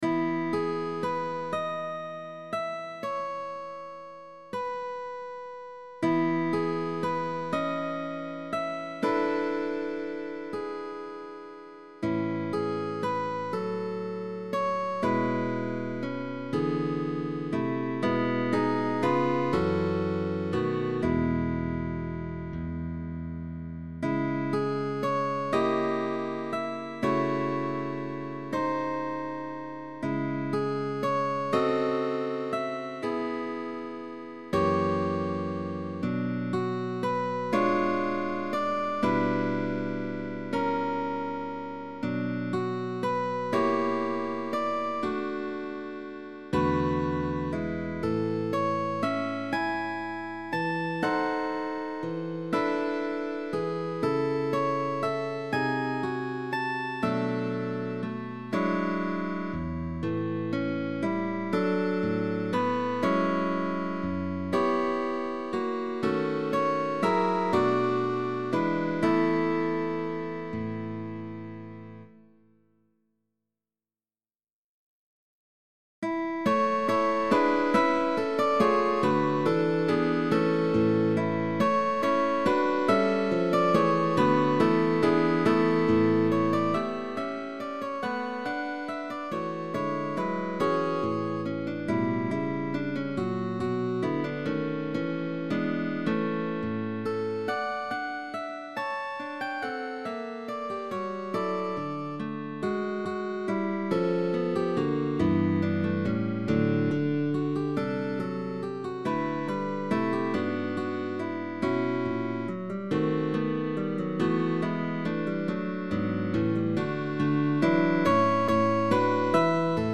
DÚO DE GUITARRAS Canción.